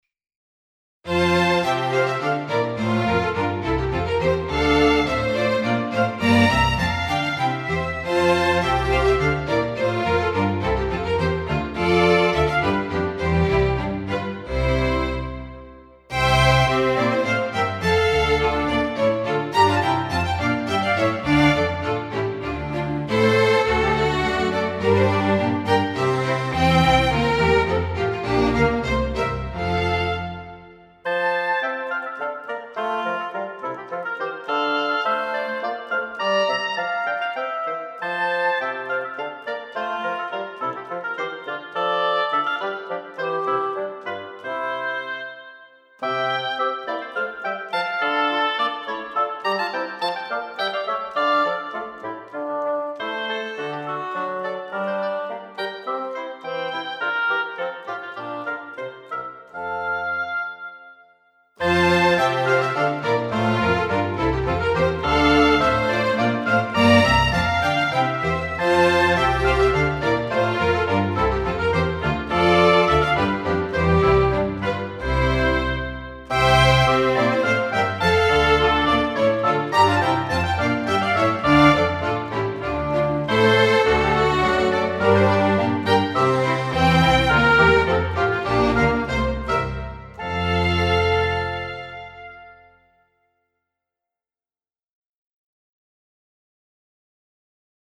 But I've done it in 2006! (remastered in 2010)
so this Handel is just for comparing the good old chamber strings and the good old mixing system(s) with the newer developments.